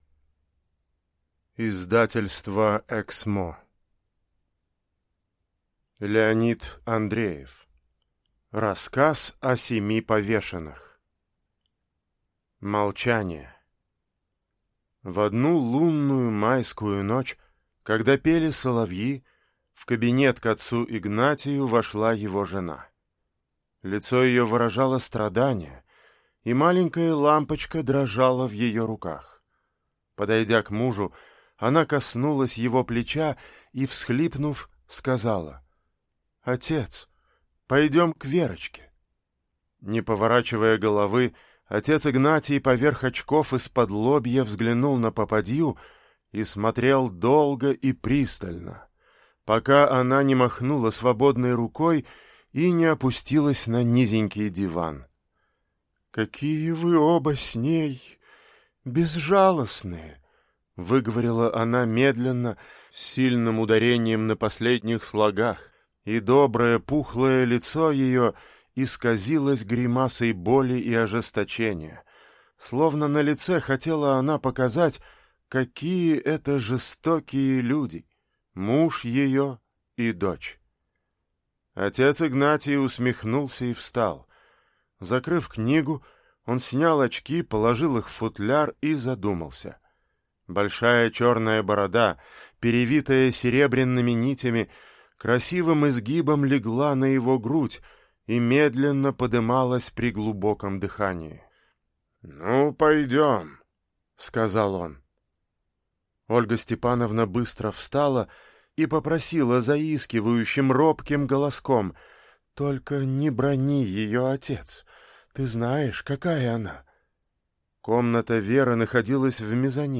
Аудиокнига Рассказ о семи повешенных (сборник) | Библиотека аудиокниг